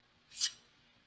scalpel1.ogg